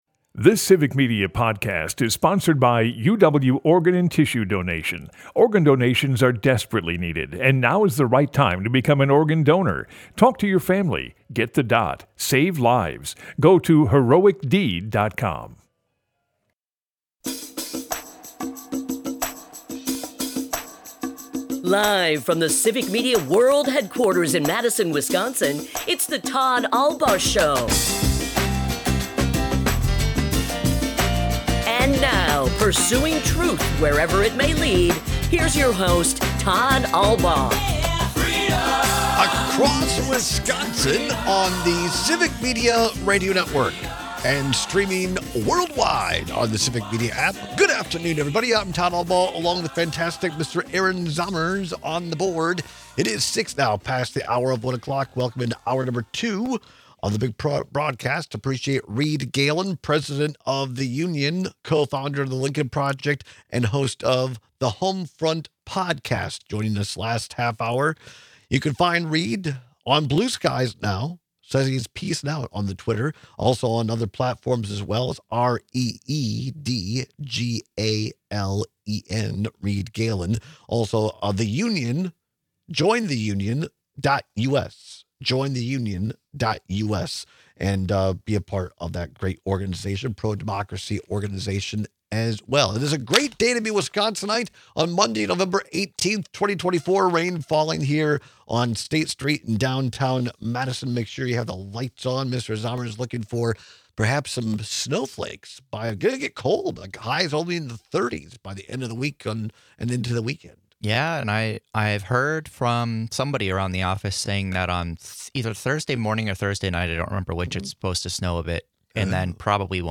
Broadcasts live 12 - 2p across Wisconsin.
We also take your calls with your takeaways on the current state of America. In other election news, Eric Hovde has finally conceded the Wisconsin senate election to incumbent Tammy Baldwin.